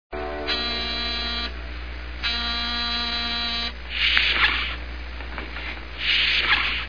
DOOR SOUNDS
Door 02 ---------------------------------- 16.8 KB
Buzzer, then door open and close
door02.wav